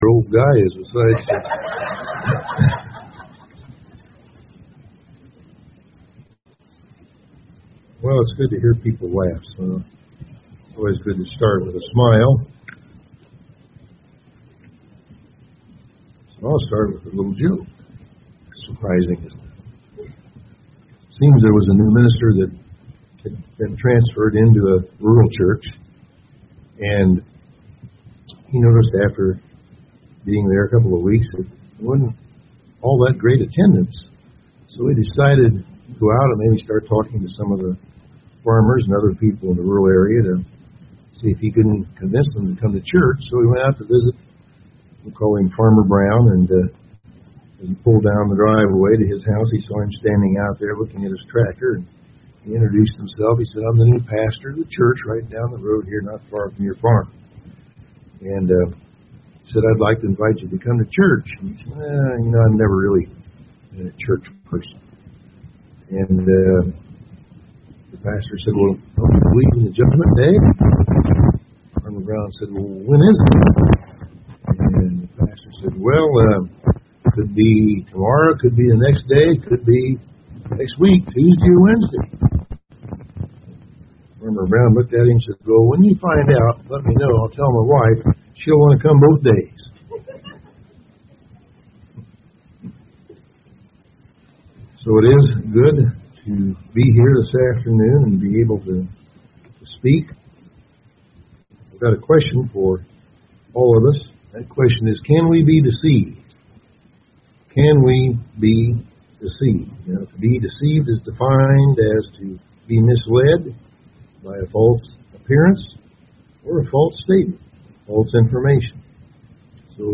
Given in Indianapolis, IN